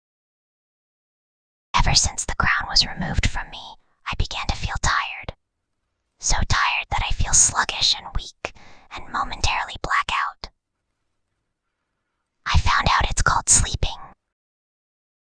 File:Whispering Girl 38.mp3 — Amaranth Legacy
Whispering_Girl_38.mp3